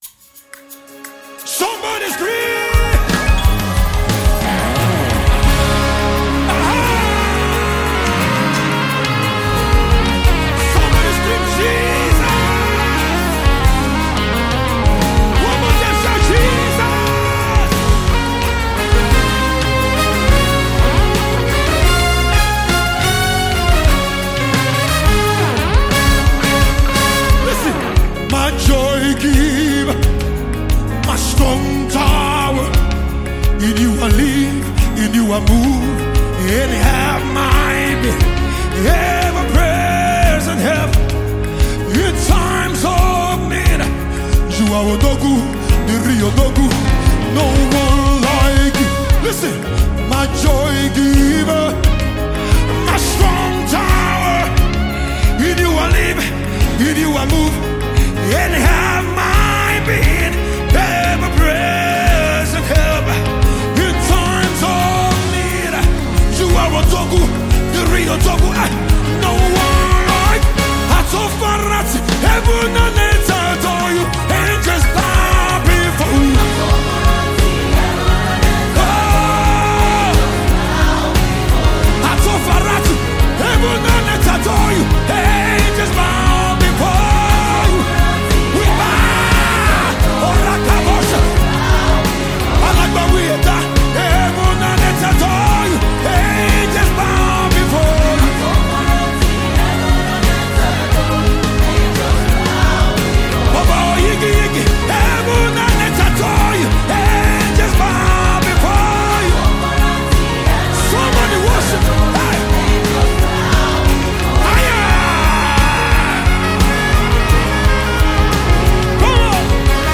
GOSPEL No Comments
dynamic contemporary style
distinctive Yoruba gospel expression